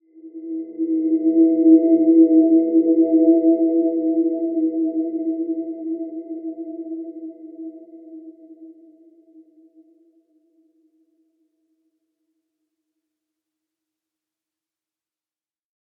Dreamy-Fifths-E4-f.wav